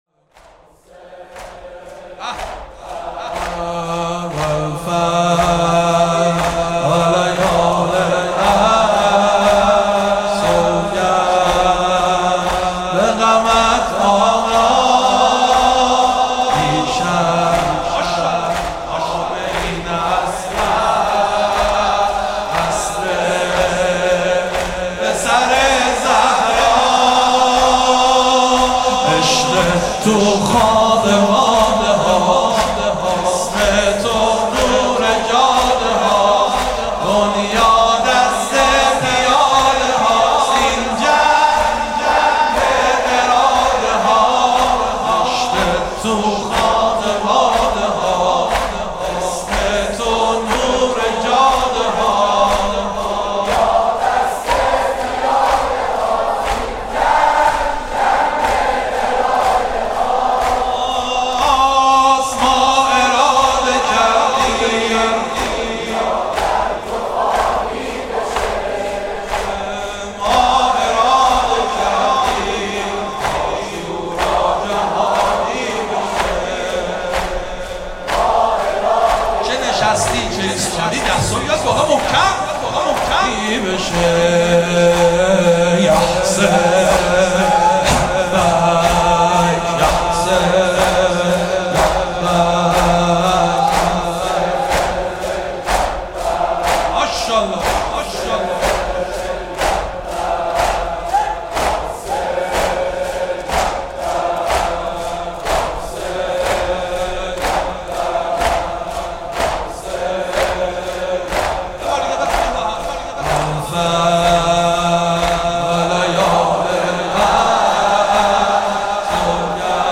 زمینه | والفجر و لیال عشر
مداحی
شب دوم محرم 1441 | مسجد ملا اسماعیل